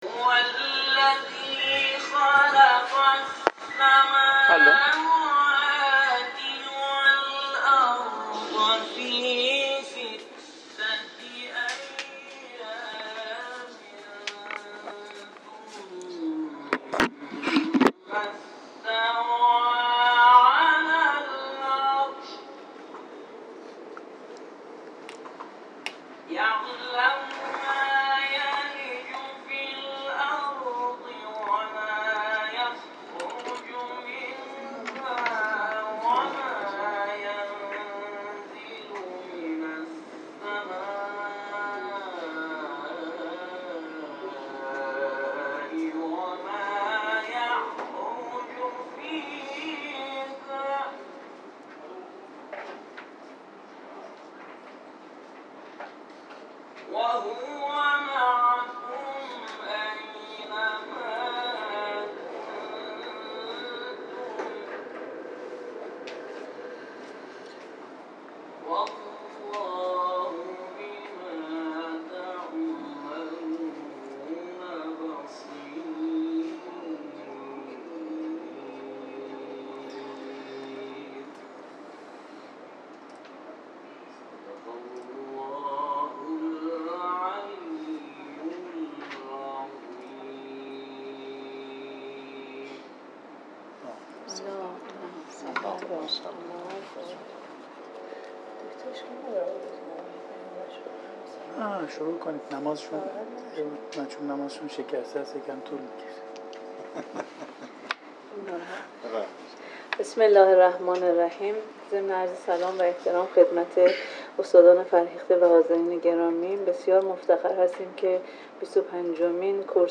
بیست و پنجمین کرسی ترویجی با رویکرد عرضه و نقد ایده علمی